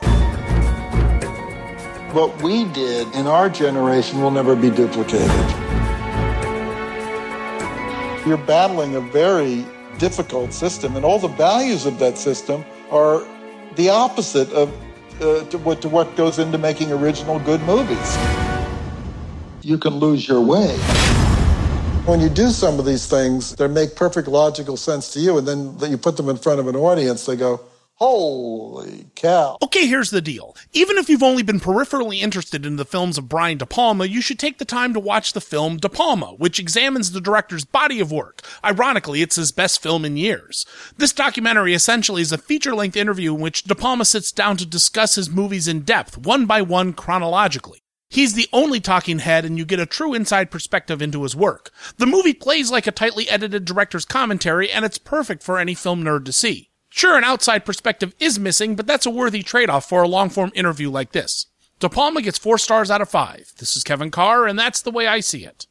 ‘De Palma’ Radio Review